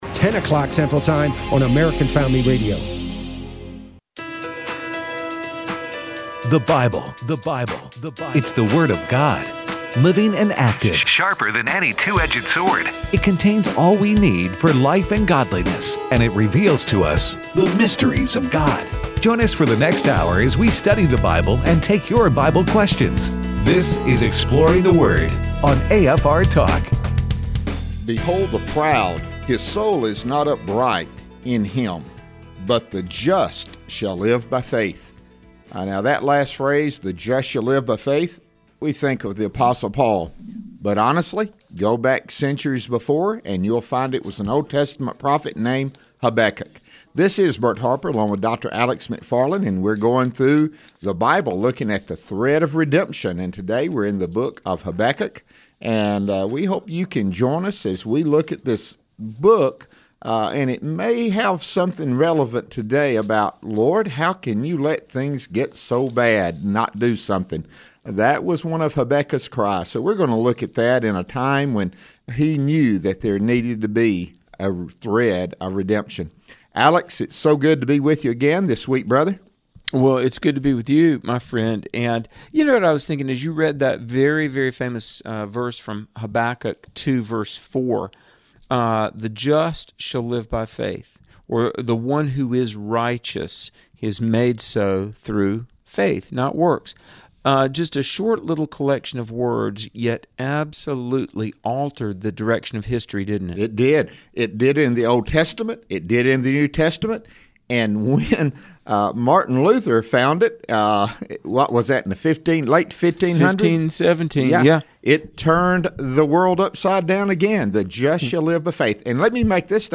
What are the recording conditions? takes your phone calls in the last segment.